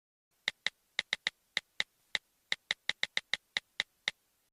جلوه های صوتی
دانلود صدای تایپ گوشی 1 از ساعد نیوز با لینک مستقیم و کیفیت بالا
برچسب: دانلود آهنگ های افکت صوتی اشیاء دانلود آلبوم صدای تایپ گوشی، کیبورد و ماشین تحریر از افکت صوتی اشیاء